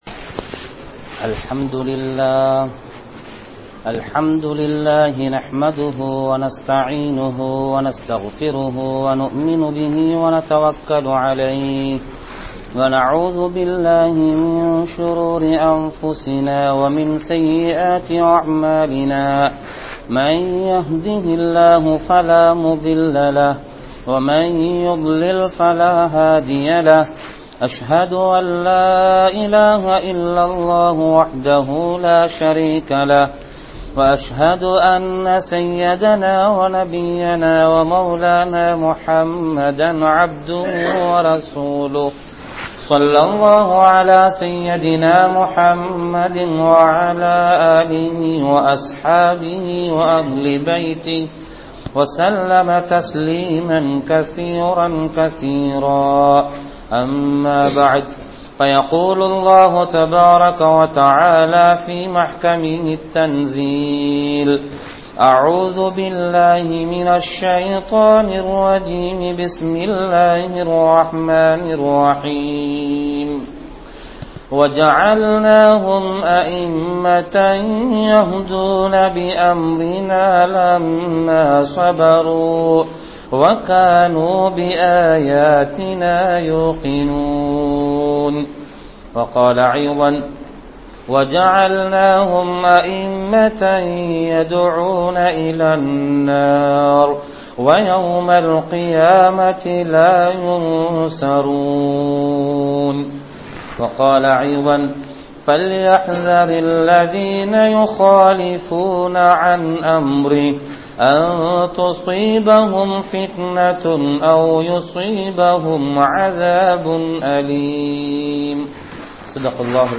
Allah`vin Niumath (அல்லாஹ்வின் நிஃமத்) | Audio Bayans | All Ceylon Muslim Youth Community | Addalaichenai